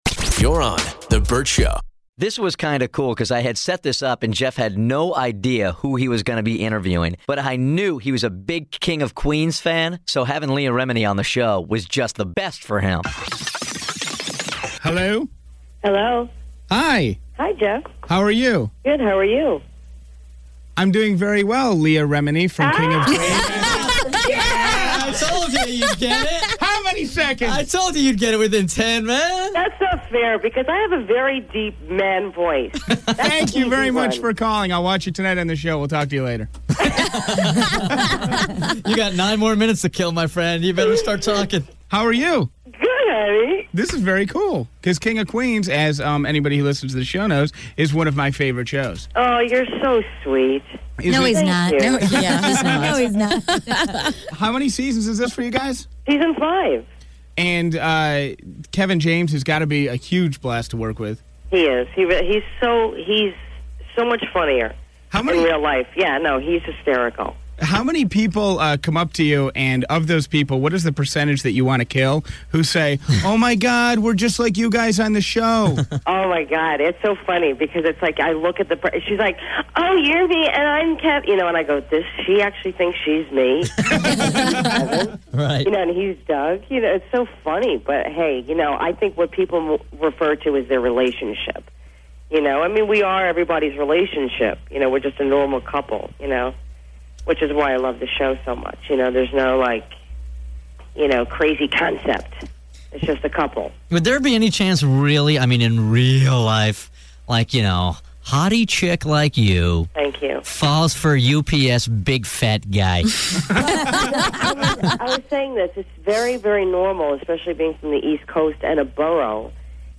Vault: Interview With Leah Remini